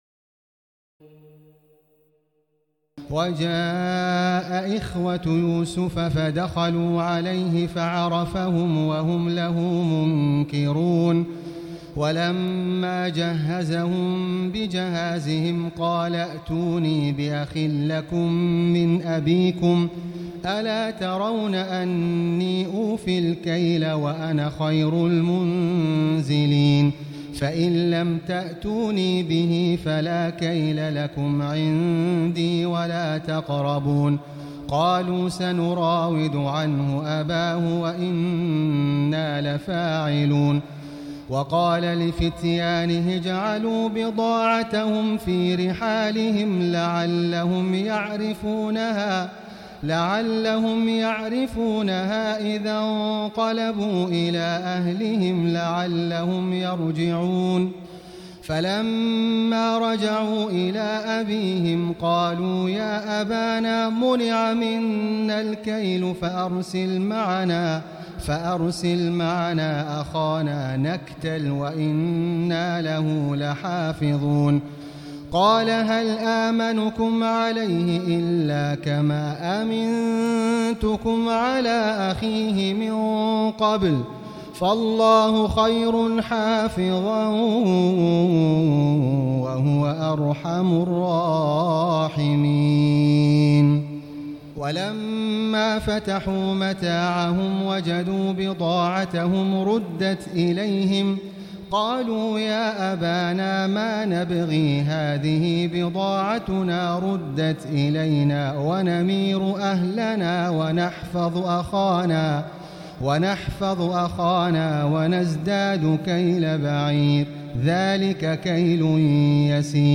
تراويح الليلة الثانية عشر رمضان 1439هـ من سورتي يوسف (58-111) و الرعد (1-18) Taraweeh 12 st night Ramadan 1439H from Surah Yusuf and Ar-Ra'd > تراويح الحرم المكي عام 1439 🕋 > التراويح - تلاوات الحرمين